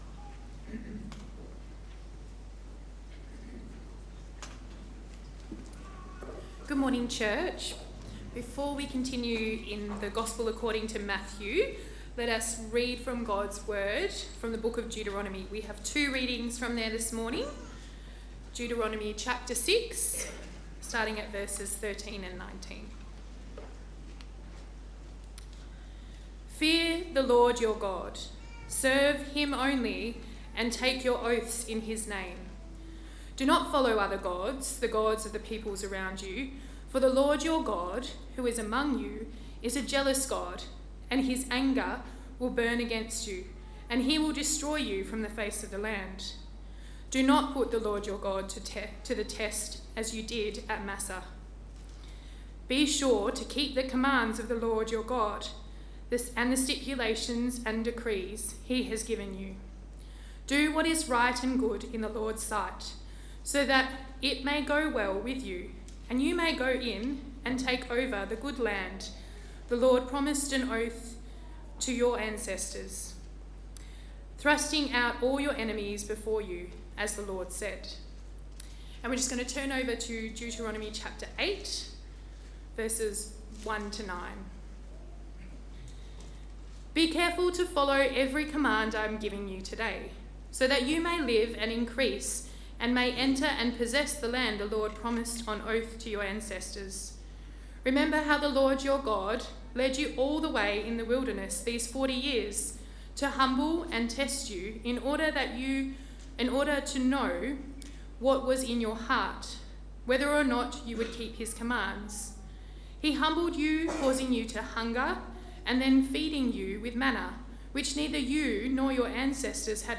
Sermons by Willetton Christian Church